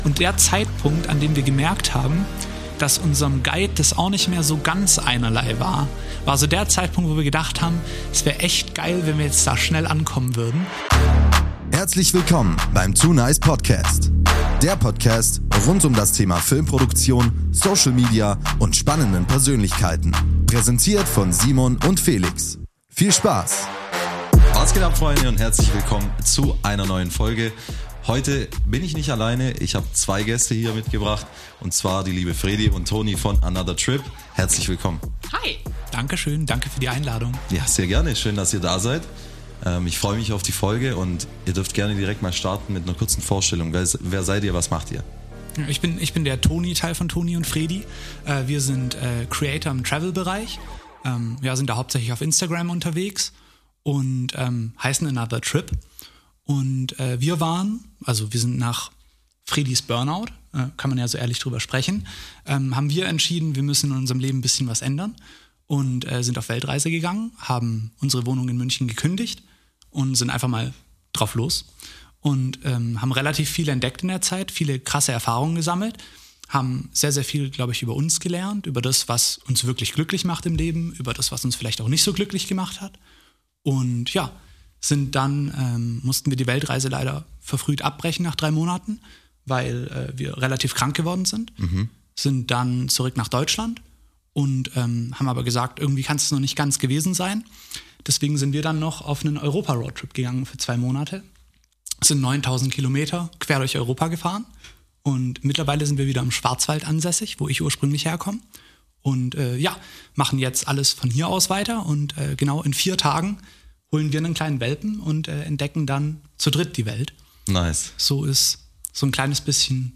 Interview ~ 2nice Podcast I Film- & Social Media Agentur Podcast